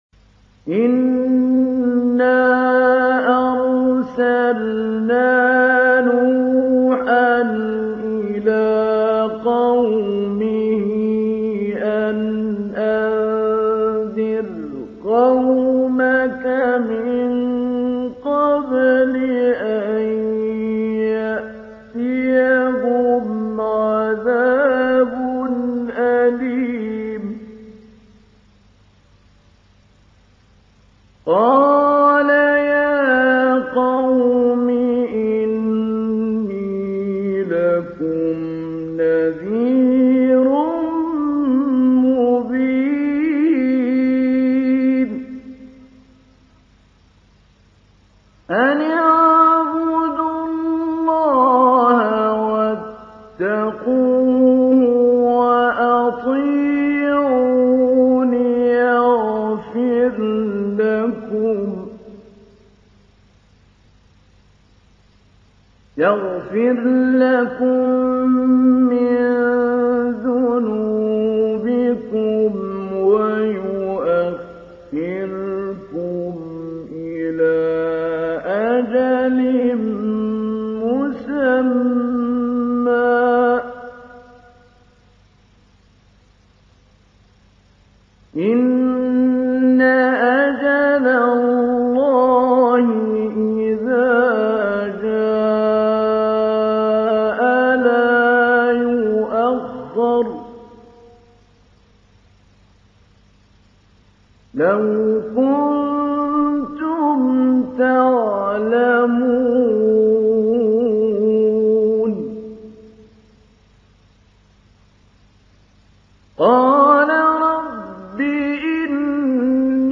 تحميل : 71. سورة نوح / القارئ محمود علي البنا / القرآن الكريم / موقع يا حسين